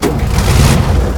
tank-engine-start-1.ogg